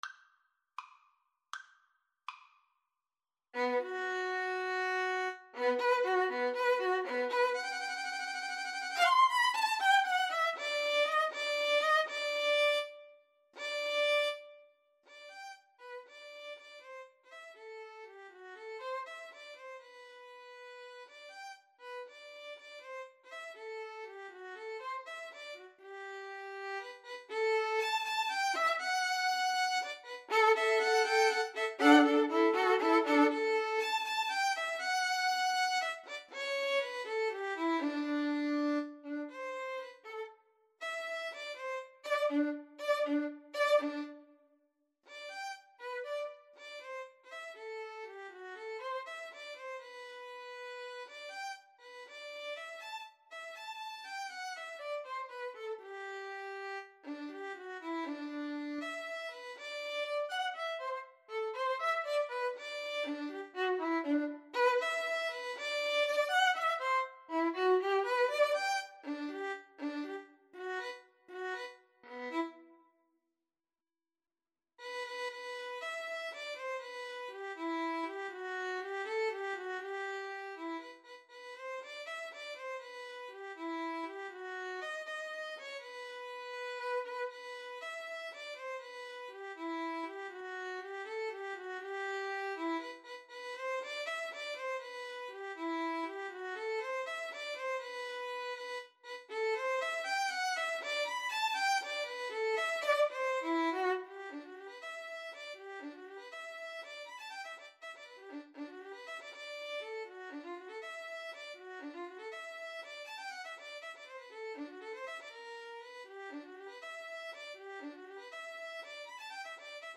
Free Sheet music for Violin-Viola Duet
G major (Sounding Pitch) (View more G major Music for Violin-Viola Duet )
6/8 (View more 6/8 Music)
~ = 100 Allegretto moderato .=80
Classical (View more Classical Violin-Viola Duet Music)